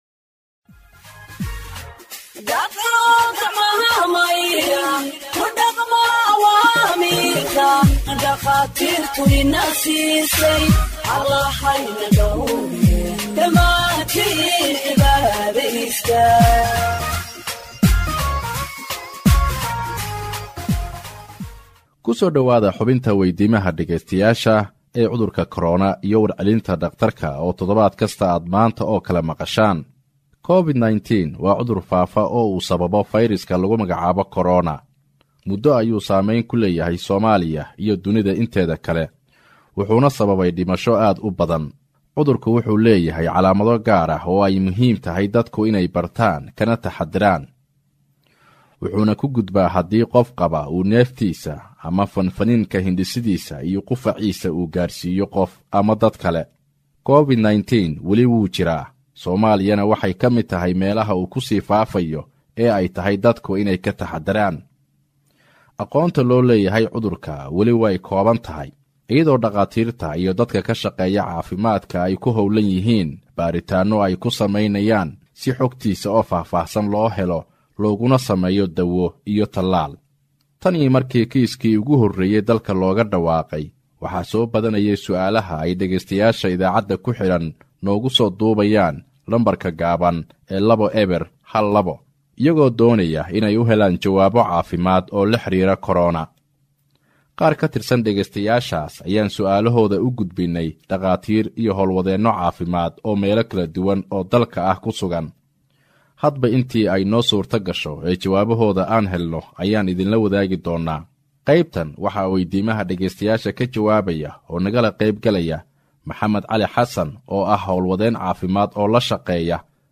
HEALTH EXPERT ANSWERS LISTENERS’ QUESTIONS ON COVID 19 (67)